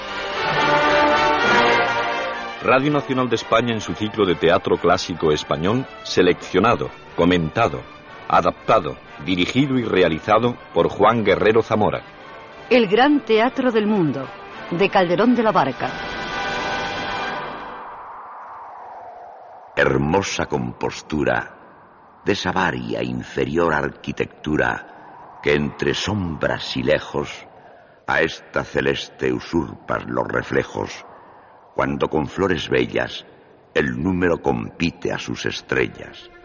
"Ciclo de teatro clásico español", careta del programa i fragment de "El gran teatro del mundo", de Calderón de la Barca.
Ficció